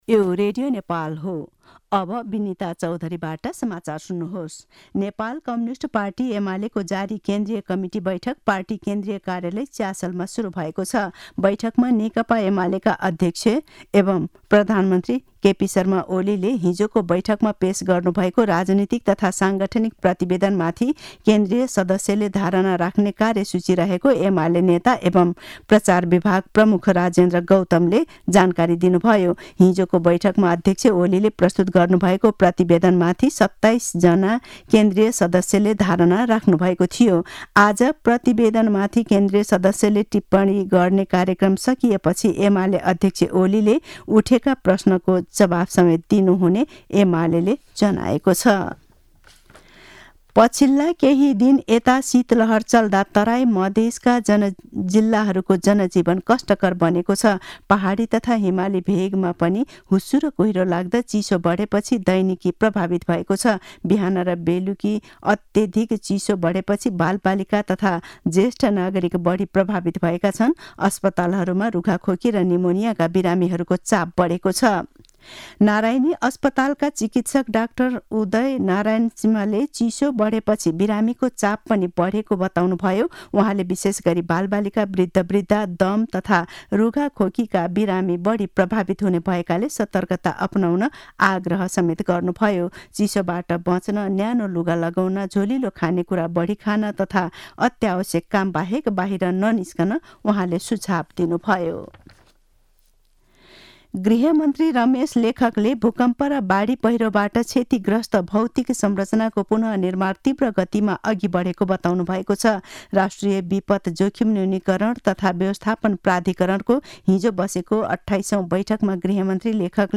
मध्यान्ह १२ बजेको नेपाली समाचार : २३ पुष , २०८१
12-am-news-.mp3